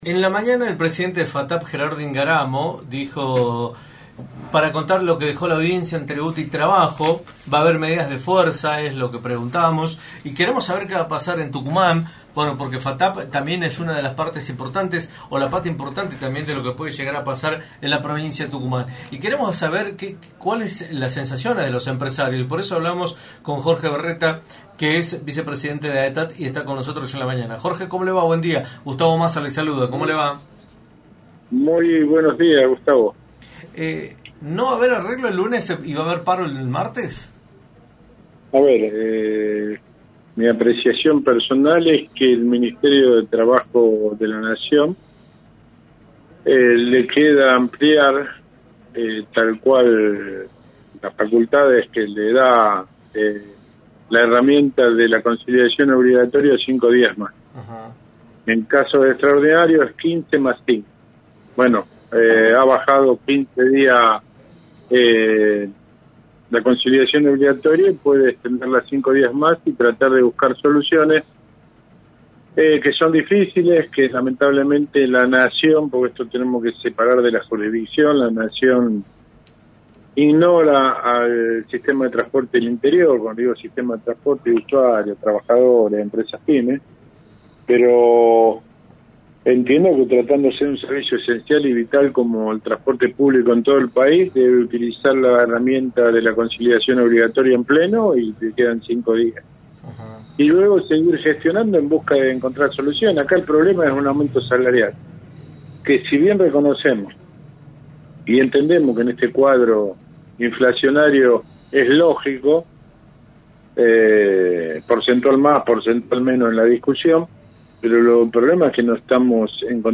en entrevista para “La Mañana del Plata” por la 93.9.